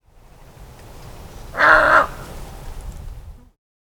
Do you hear that deep croak at the end of the Pied-billed Grebe recording above? Our best guess is a Snowy Egret, but we’re not certain.
Here’s the single croak edited out by itself.